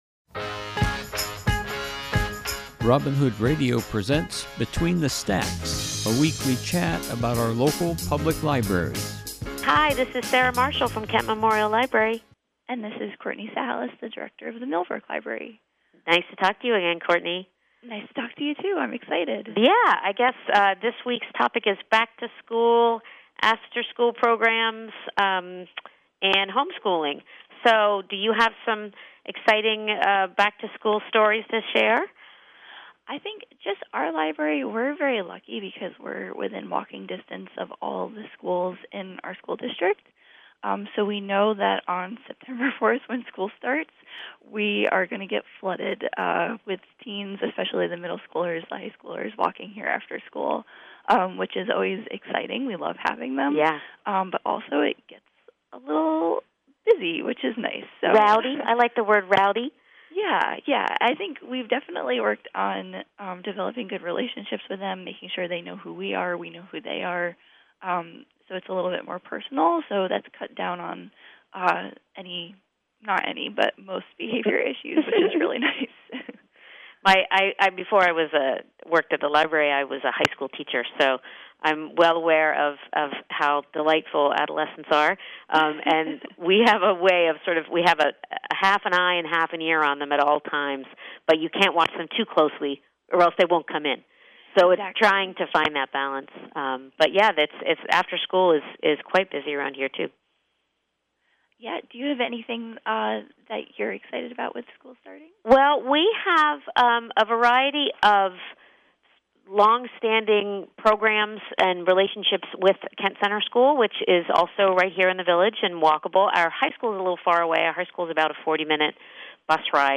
This week’s program is a conversation